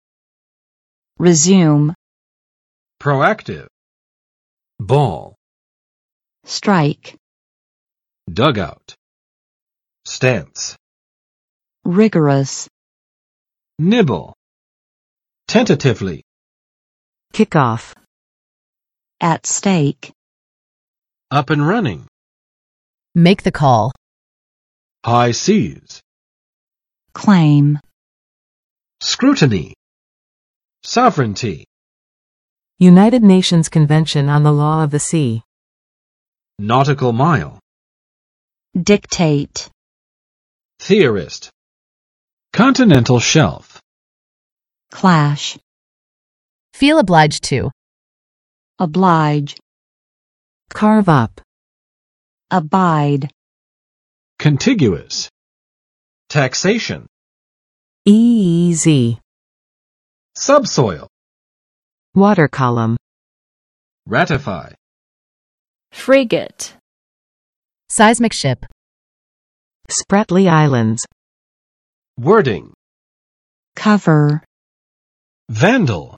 [rɪˋzjum] v. 重新开始